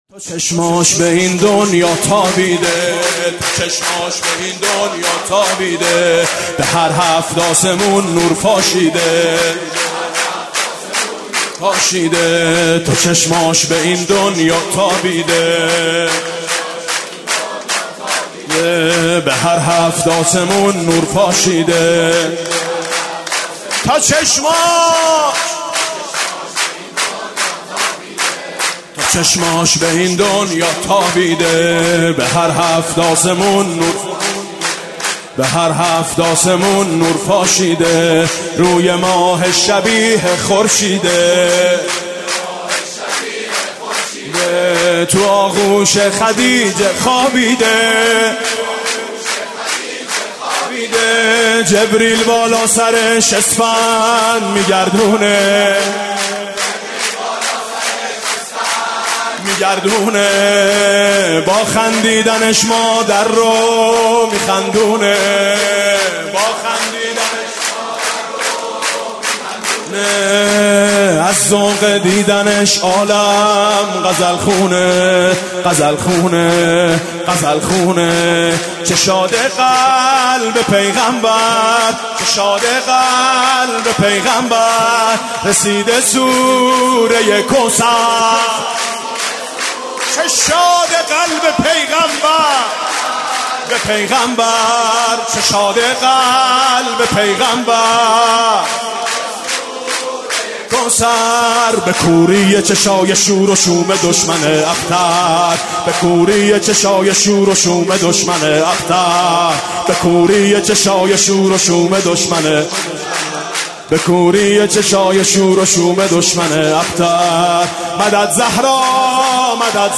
سرود: چه شاده قلبِ پیغمبر، رسیده سوره کوثر